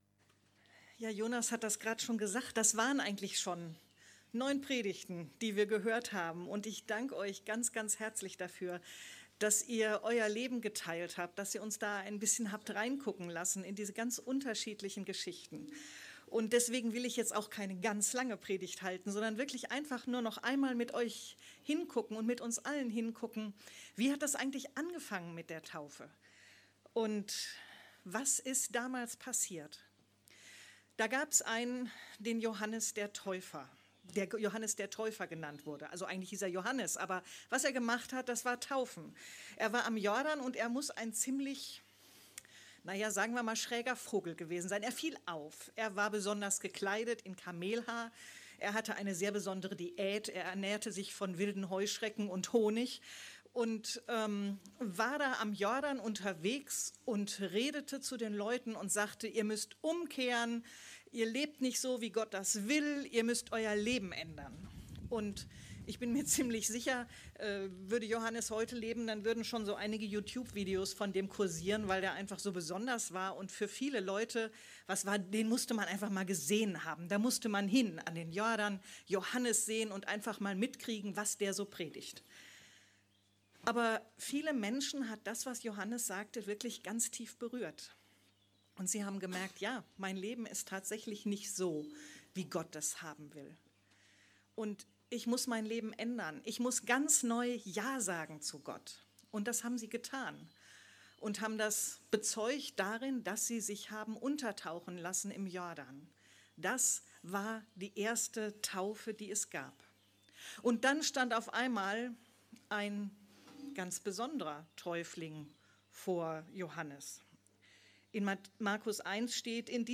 Taufgottesdienst